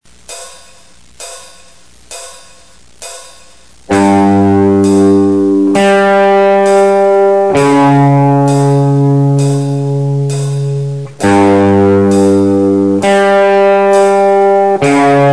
Вариации я использовал и в "Black Sabbath" [Black Sabbath]. Самый первый риф представлен в ПРИМЕРЕ 4.